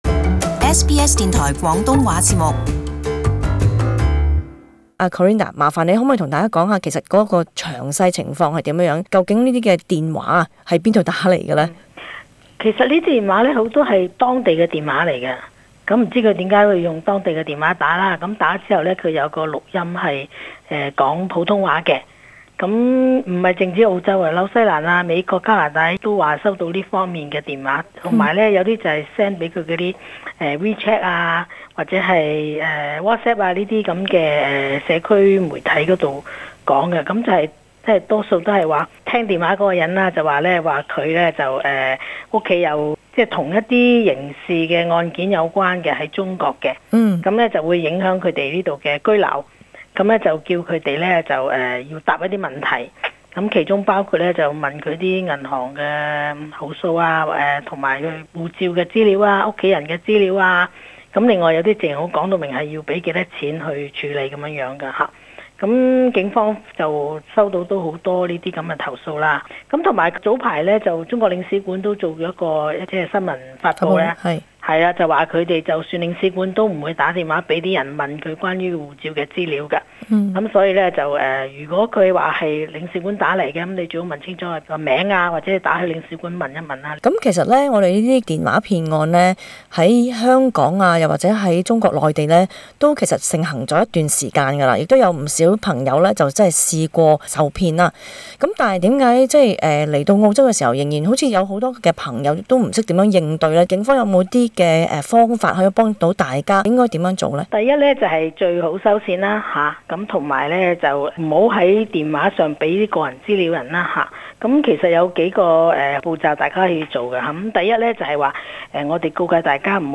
【時事專訪】針對華人的電話騙局湧現澳洲